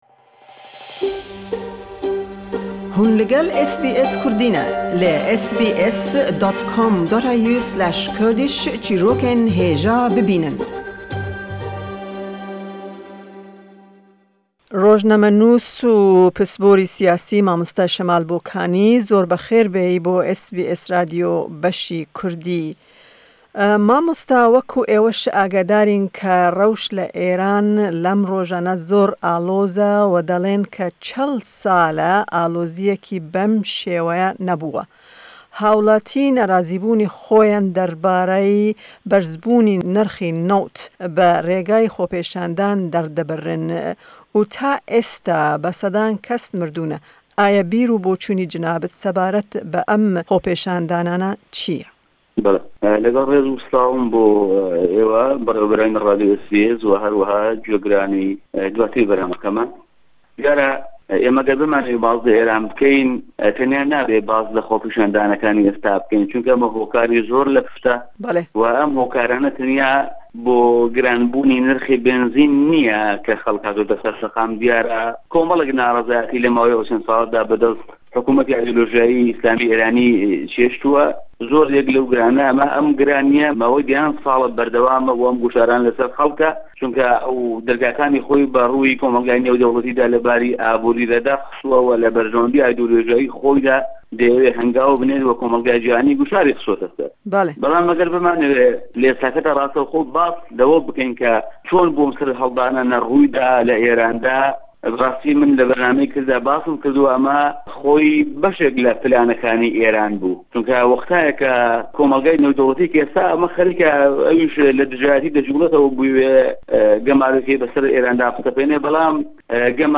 Hevpeyvîn derbarî rewş û xwepêşandanên ku li Îranê cî digirine.